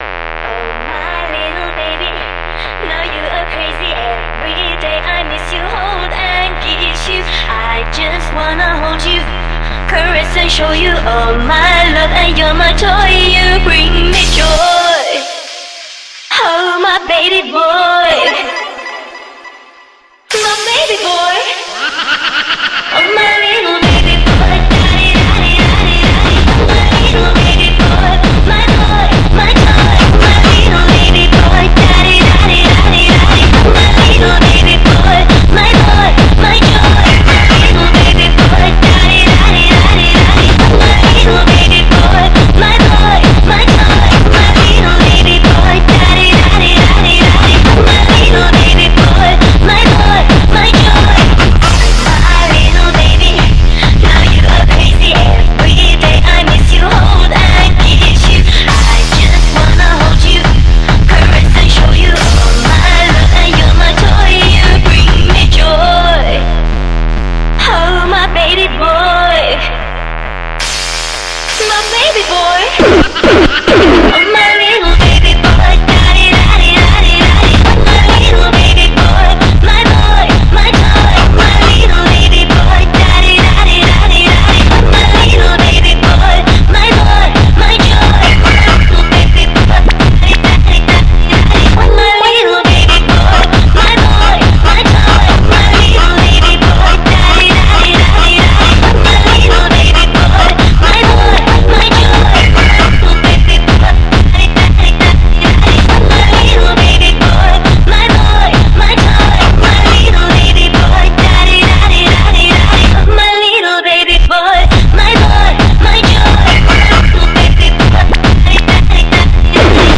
Зарубежная